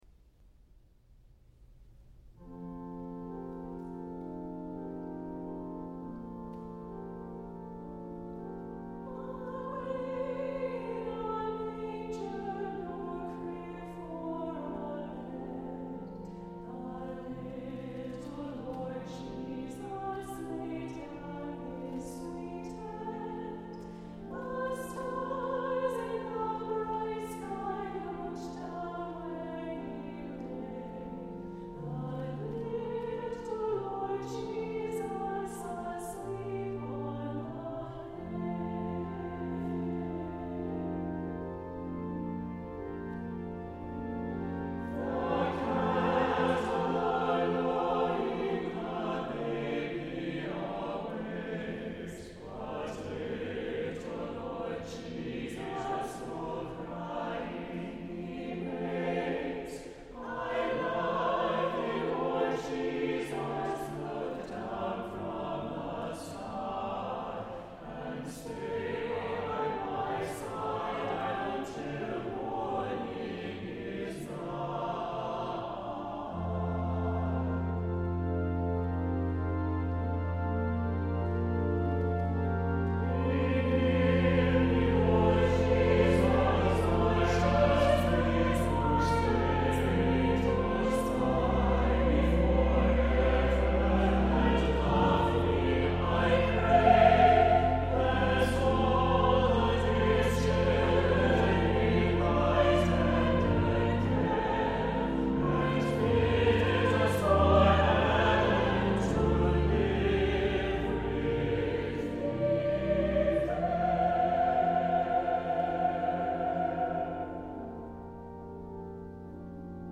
• Music Type: Choral
• Voicing: SATB
• Accompaniment: Organ
• Season: Christmas
An imaginative arrangement